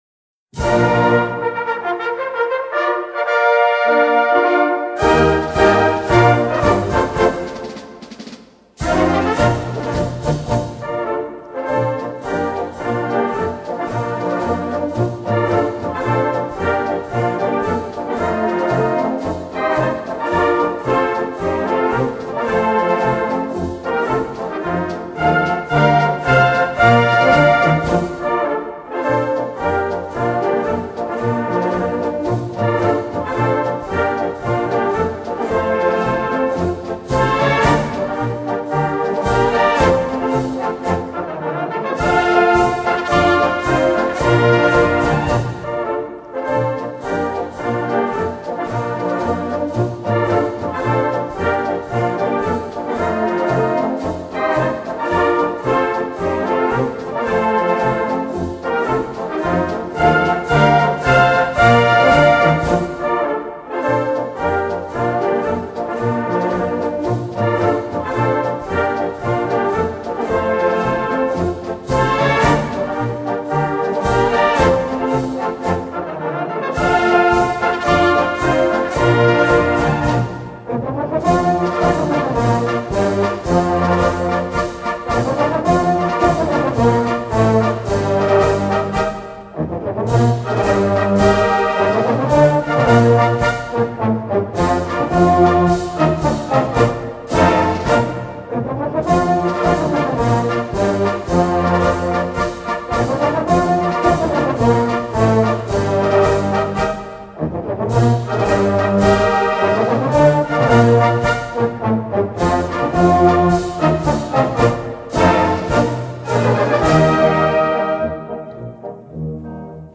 Gattung: Konzertmarsch
Besetzung: Blasorchester
Ein großartiger Konzertmarsch